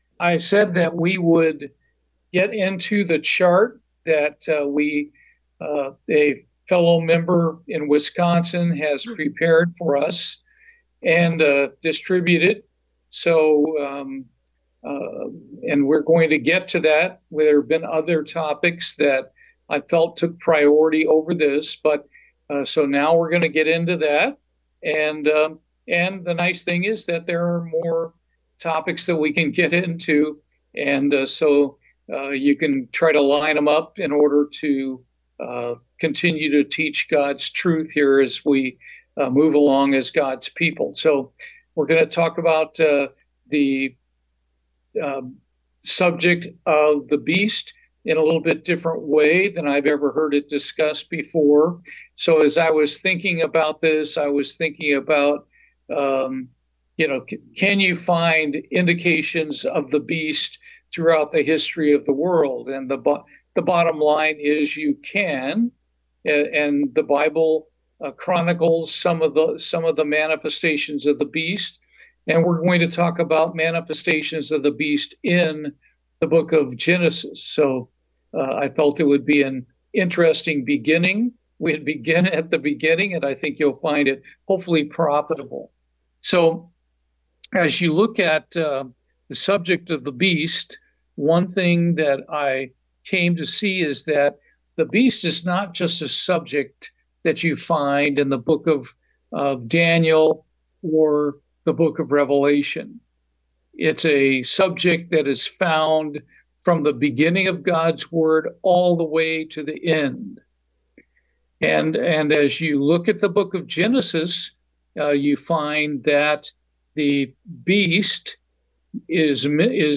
Bible Study, The Beast in Genesis
Given in Houston, TX